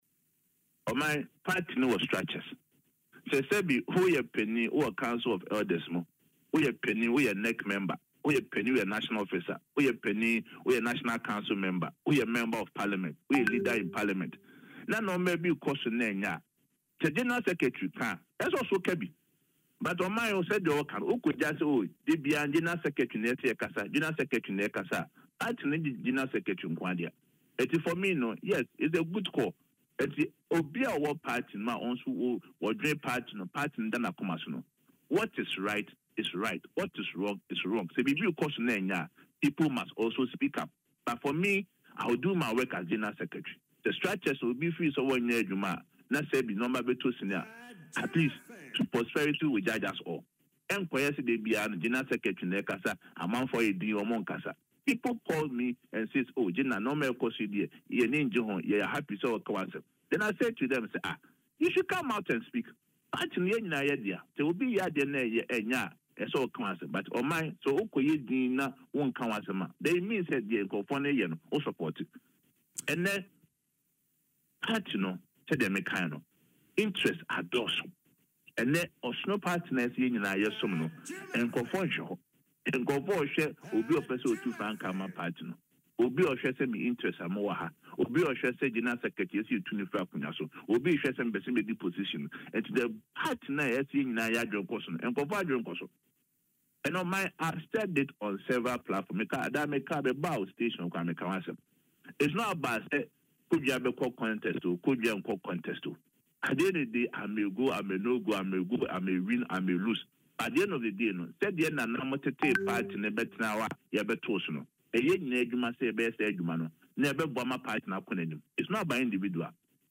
Speaking in an interview on Adom FM’s Dwaso Nsem, Kodua expressed concern that many members are prioritizing individual ambitions over the collective good of the NPP.